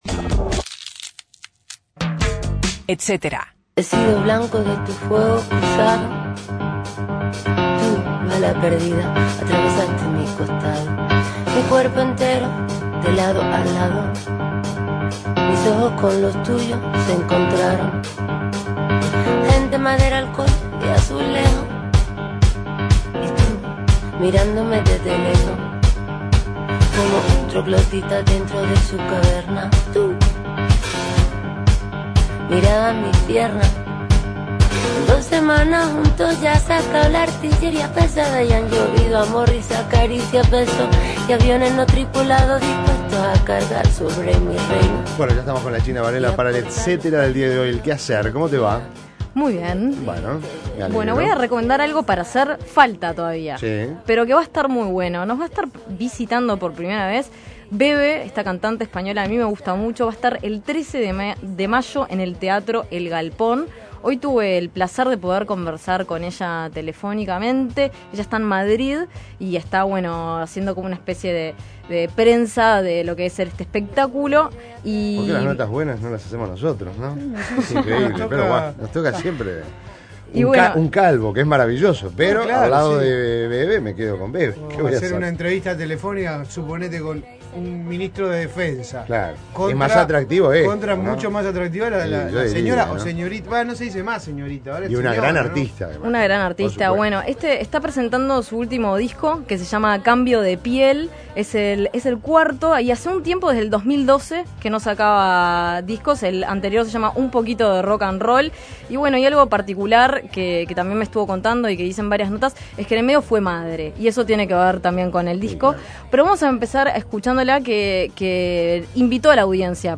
Conversamos con ella sobre su último álbum, un trabajo que definió "como un paso de madurez" que posee "mucha más luz" que los anteriores; asimismo destacó el protagonismo del piano en el álbum.